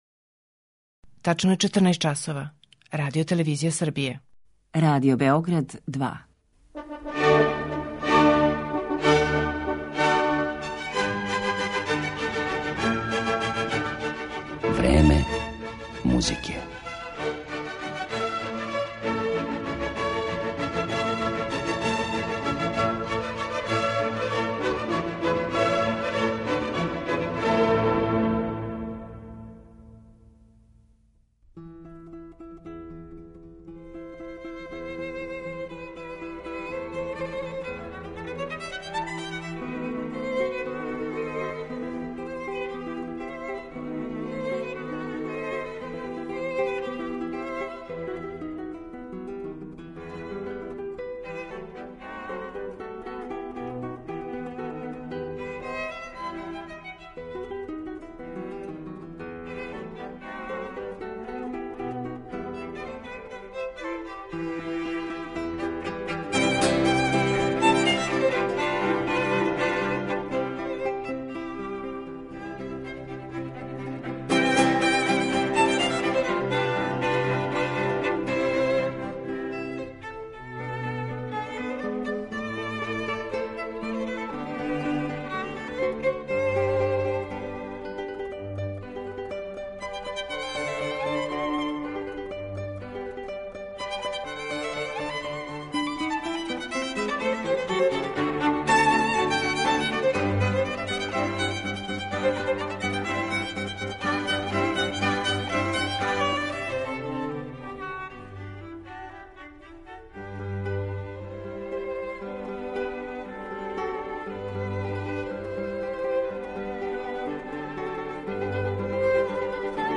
Гитариста Пепе Ромеро
Данас ћемо га представити квинтетима за гитару и гудачки квартет Луиђија Бокеринија.